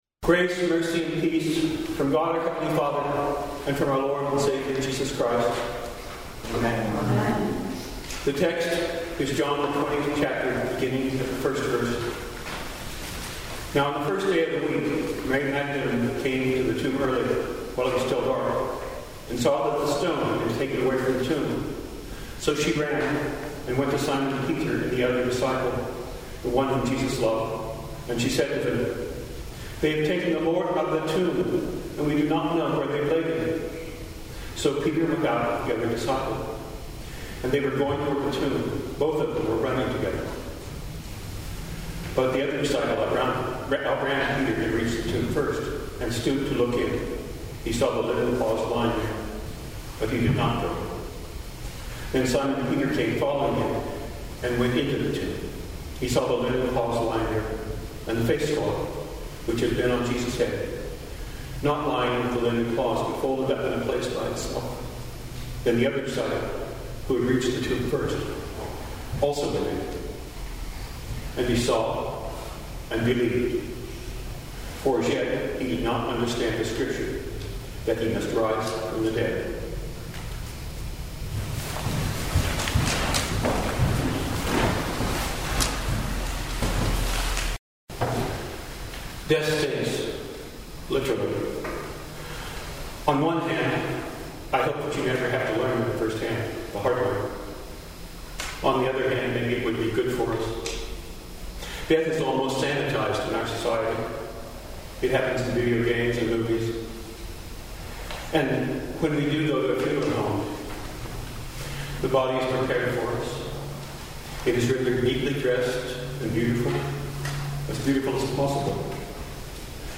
Sermon Only « He Has Risen!